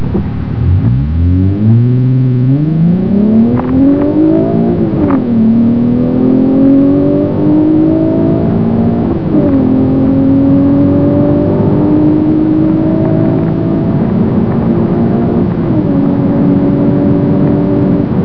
The sound is much more quiet at idle yet loud enough under hard acceleration. I have never experienced any amount of droning, to date.